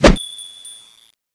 xmas_slash.wav